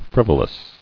[friv·o·lous]